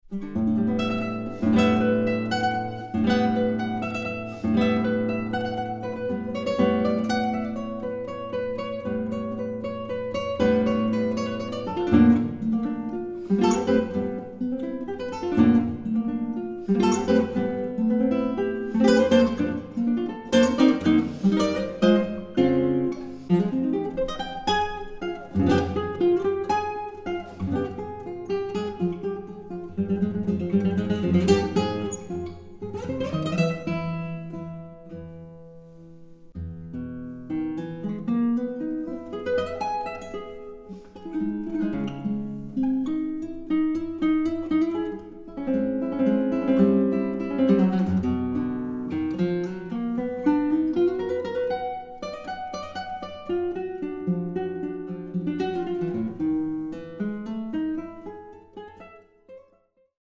Eine musikalische Collage kanonischer Gitarrenwerke
Gitarre